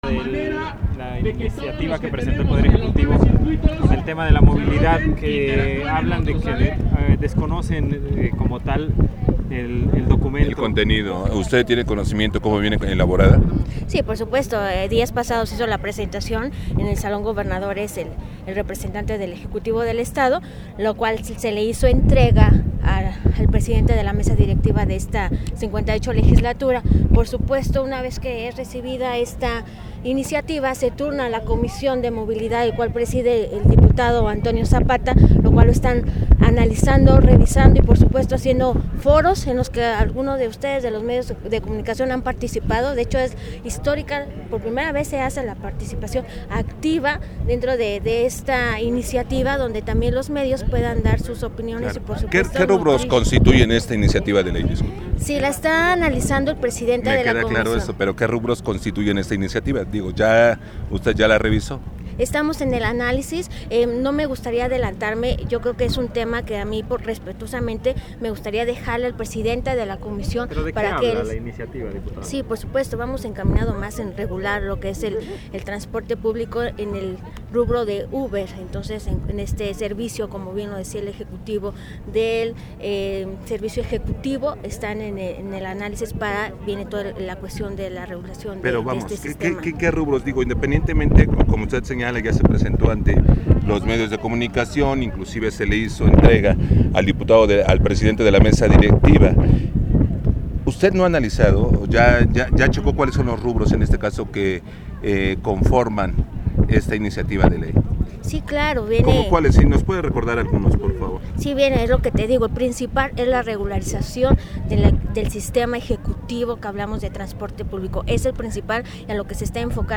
Entrevista en la que diputada, cantinflea para evadir preguntas de reporteros:
Y la entrevista continúa acontinuación el audio integro.